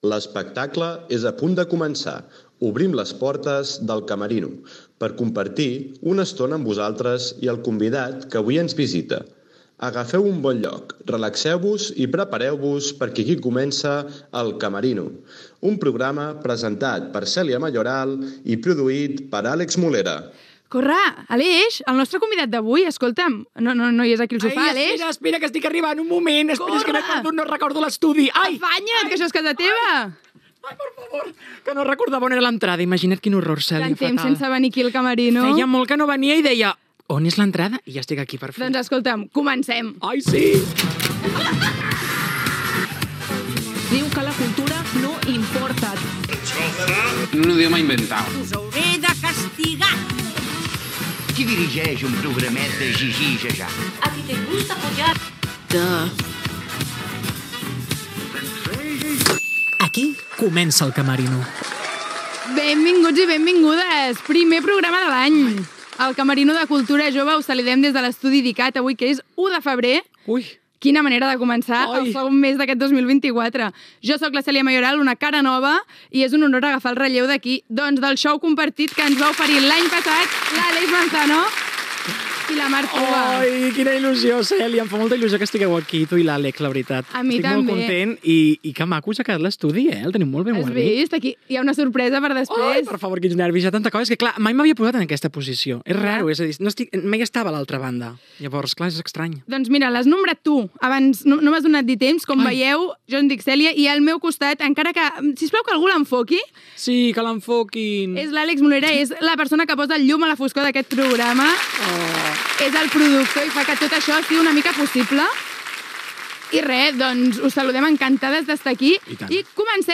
Careta del programa, presentació